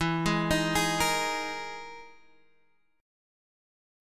EM#11 chord